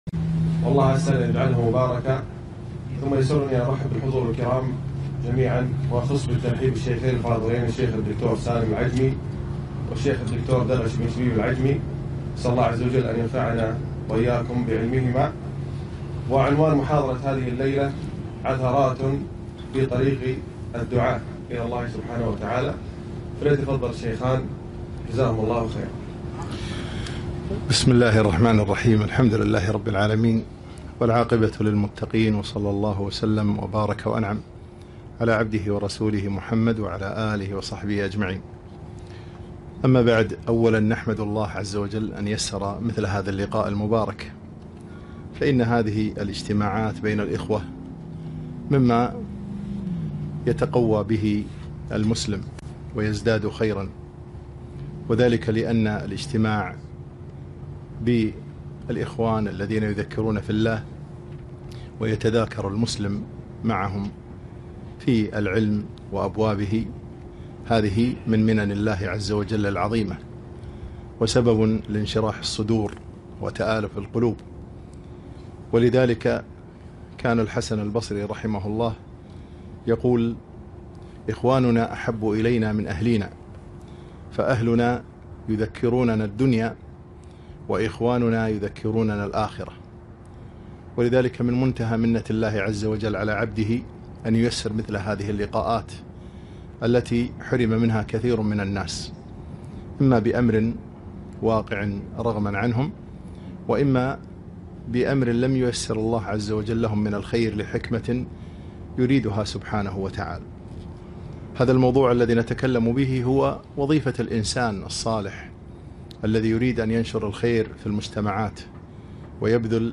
محاضرة - عثرات في طريق الدعاة